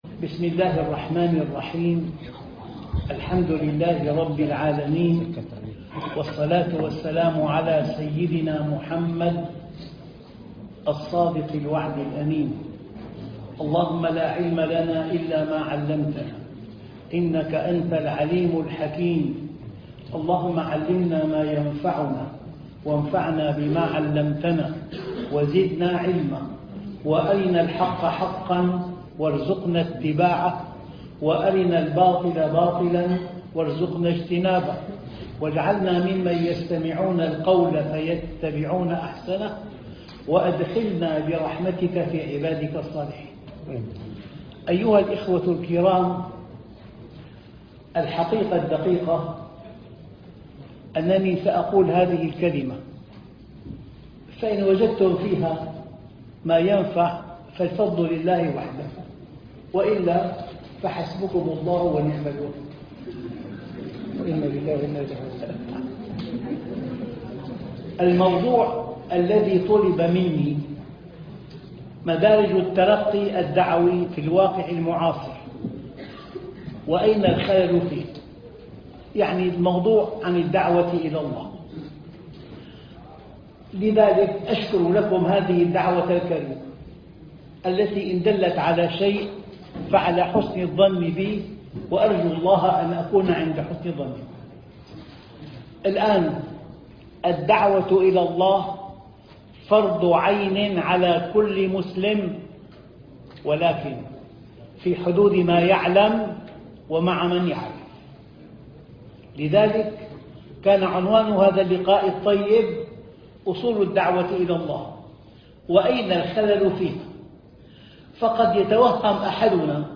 الدعوة إلى الله- اسطنبول المحاضرة 18 - الملتقى السنوي للأئمة والدعاة في أوروبا - الشيخ محمد راتب النابلسي